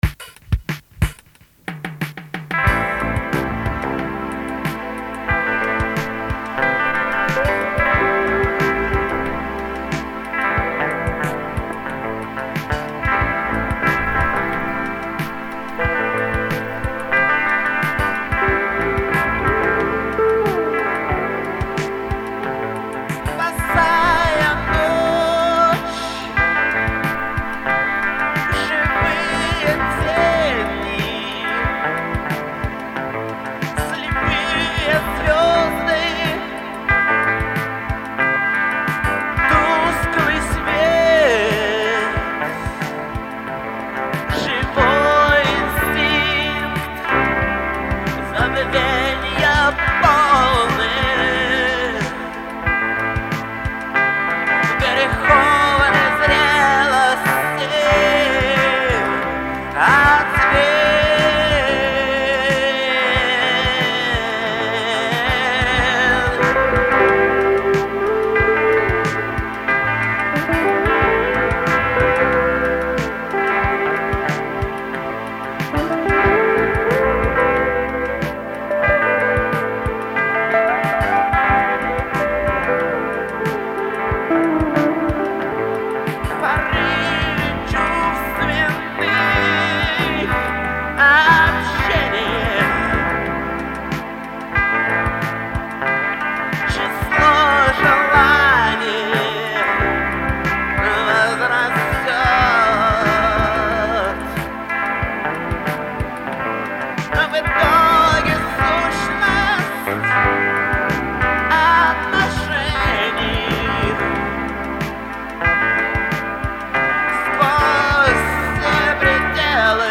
Сборник пробных записей, этюдов, репетиций
музыка, вокал (1, 2, 7, 9), гитары
барабаны, перкуссия
клавиши
вокал (3, 4, 5, 8), бэк-вокал, бас-гитара